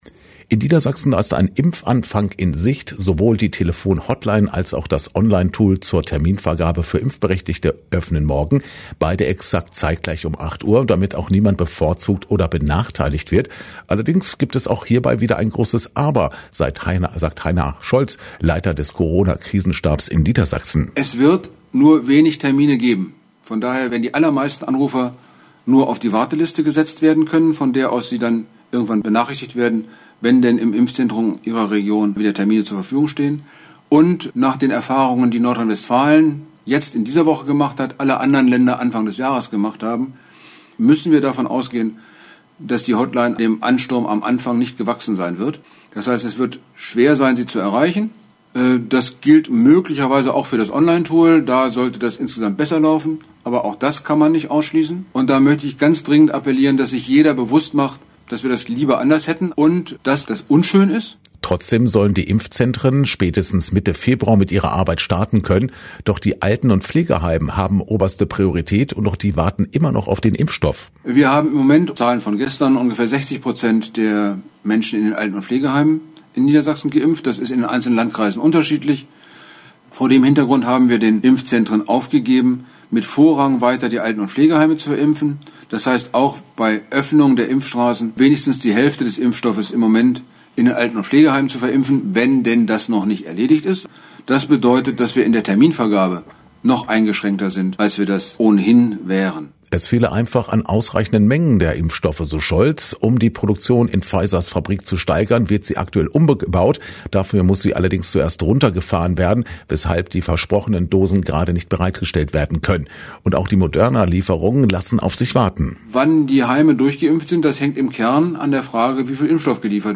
Landkreis Hameln-Pyrmont: KRISENSTAB LANDESPRESSEKONFERENZ